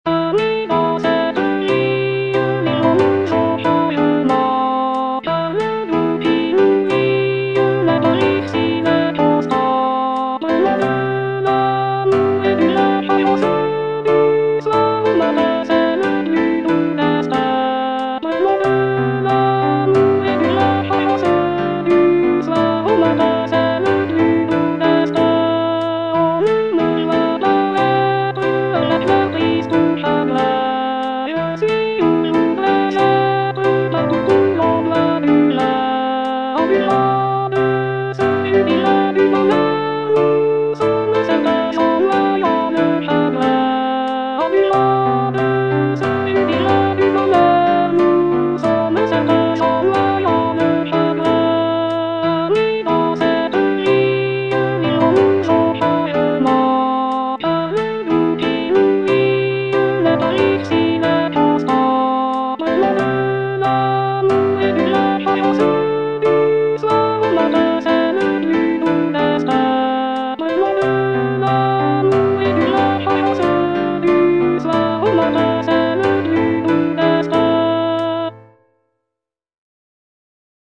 ANONYMOUS (ARR. R. DEMIEVILLE) - AMI, DANS CETTE VIE Alto (Voice with metronome) Ads stop: auto-stop
The piece features a beautiful melody accompanied by gentle harmonies, creating a soothing and heartfelt atmosphere. The lyrics speak of friendship and loyalty, making it a popular choice for choir performances and vocal ensembles.